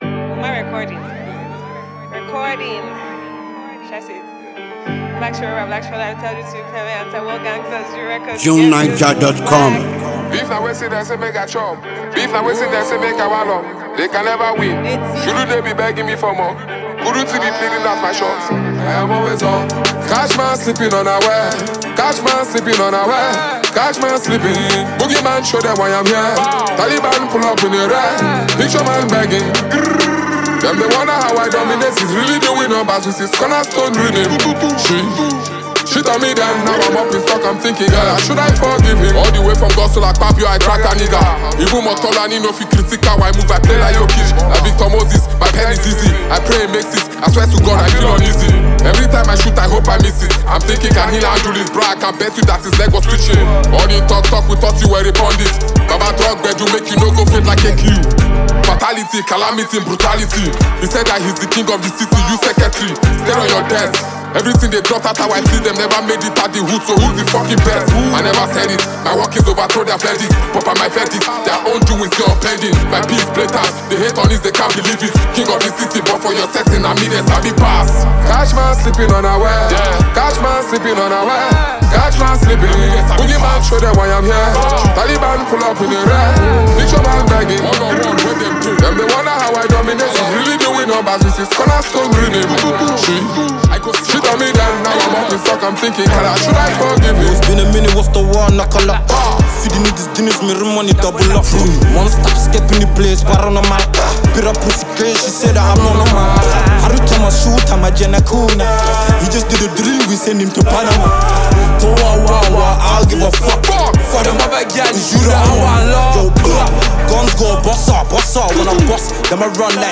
Outstanding Nigerian rapper